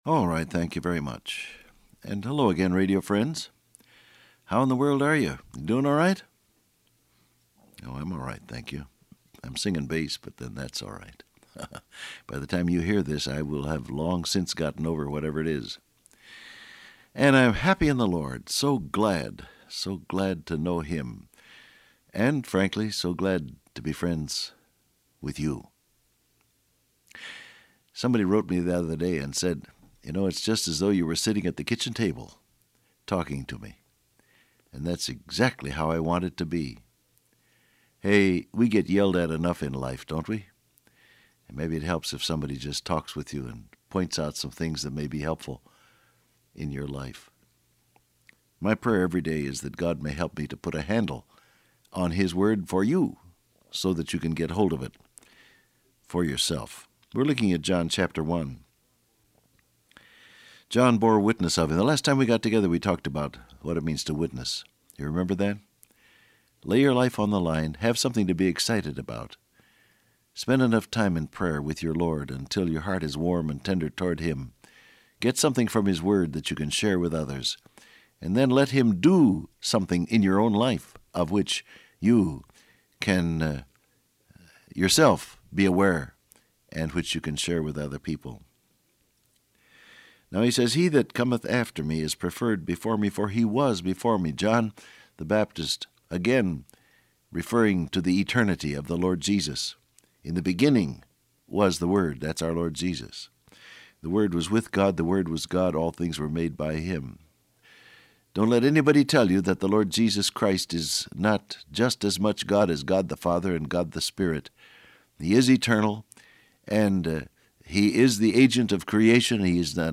I’m singing bass, but then that’s all right.